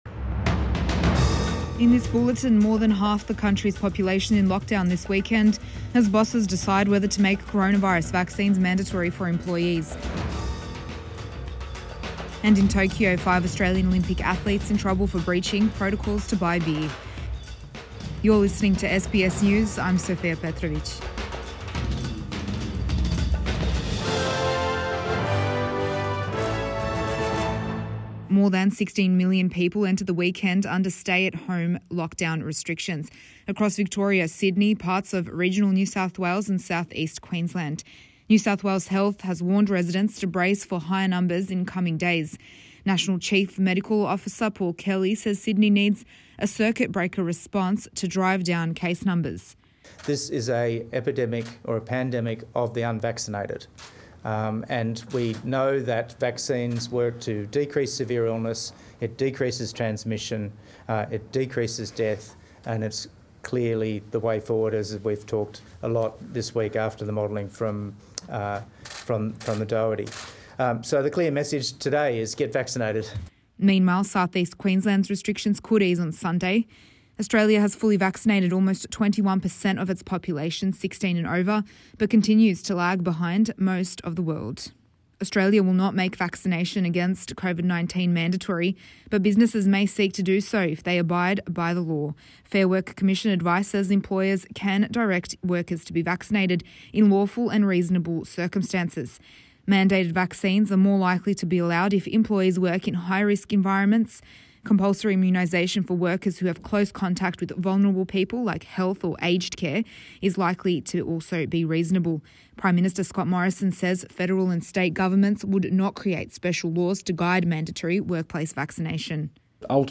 AM Bulletin 7 August 2021